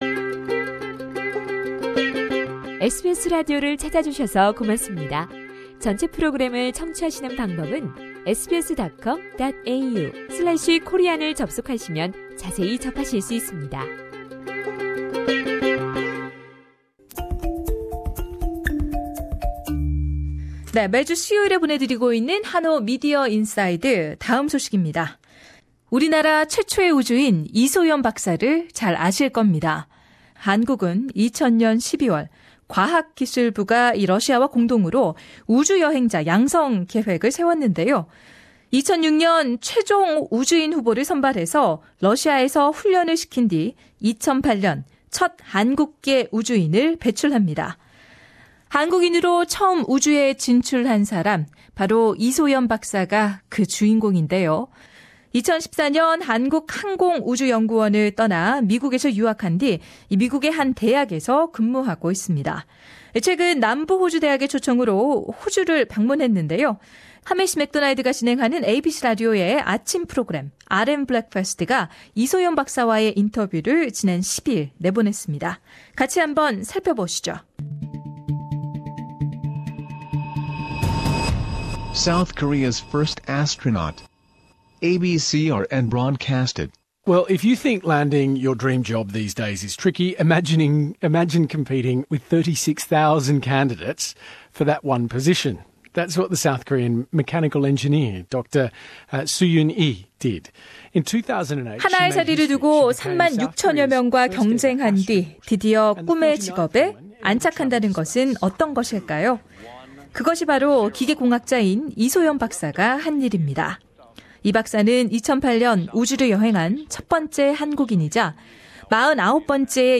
최초의 한국인 우주인 이소연 박사를 하미쉬 맥도날드가 진행하는 ABC 라디오의 아침 프로그램, RN Breakfast 가 인터뷰 했다.